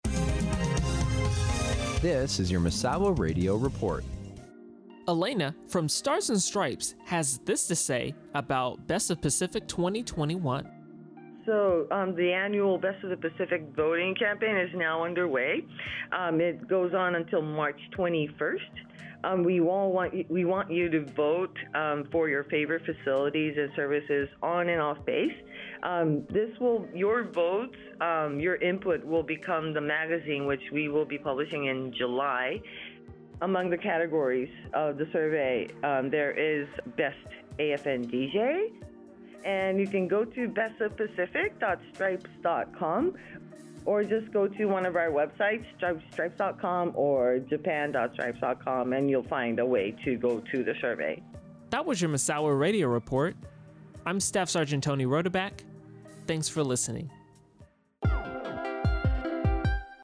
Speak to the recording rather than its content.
Pacific Stars & Stripes tells the Misawa community about the Best of Pacific survey during a live radio interview.